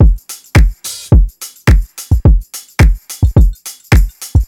• 107 Bpm HQ Drum Beat G Key.wav
Free breakbeat sample - kick tuned to the G note.
107-bpm-hq-drum-beat-g-key-Puj.wav